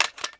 c96_clipin1.wav